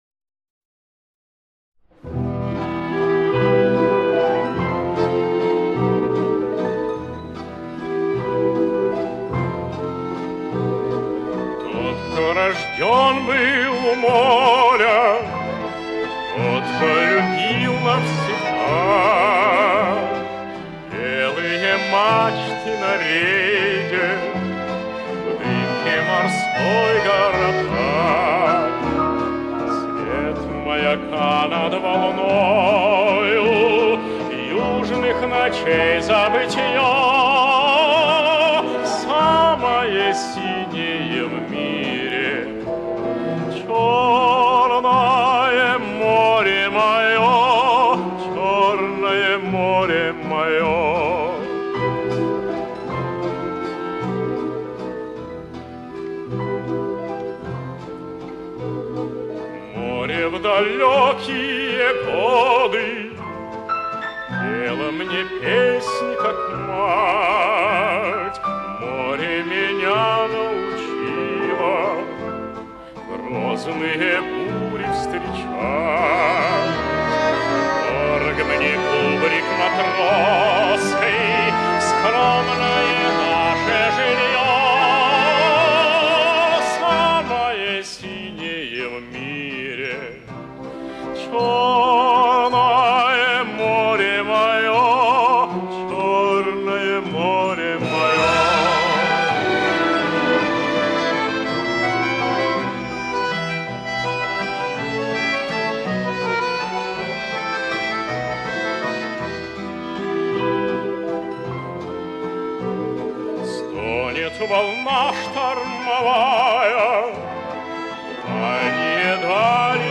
Прекрасное исполнение известной песни.